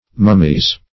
(m[u^]m"m[i^]z).